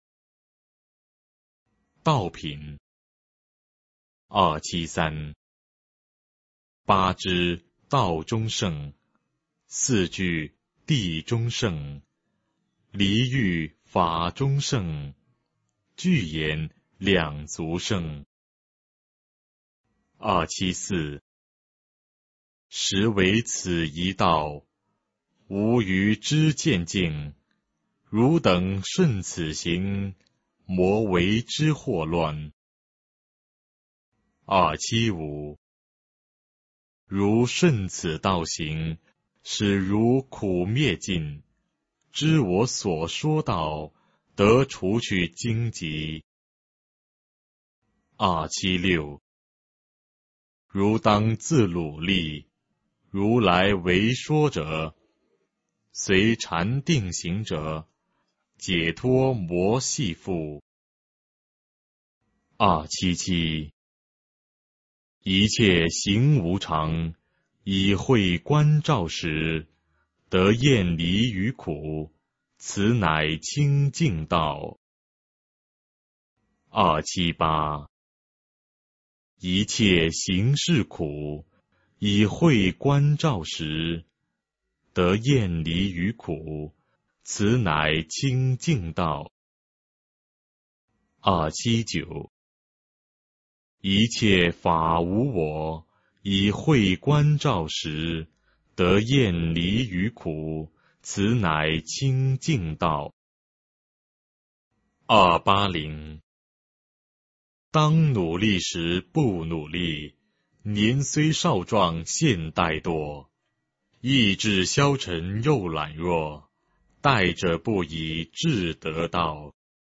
法句经-道品 诵经 法句经-道品--未知 点我： 标签: 佛音 诵经 佛教音乐 返回列表 上一篇： 法句经-刀杖品 下一篇： 法句经-恶品 相关文章 心经3--梦参法师 心经3--梦参法师...